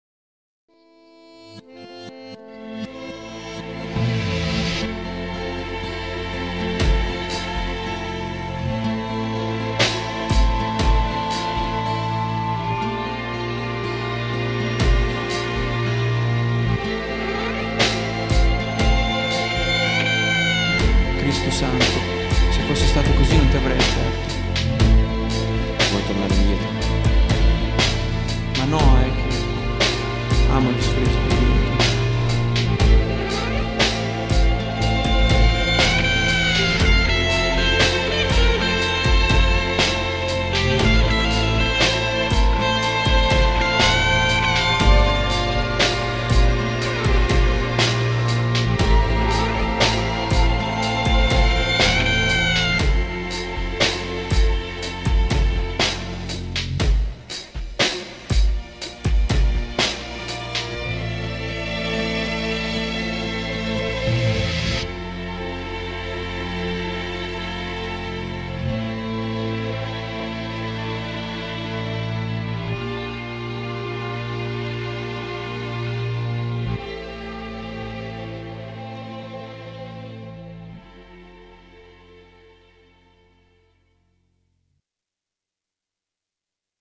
Gente al porto di Rimini
Download in qualità CD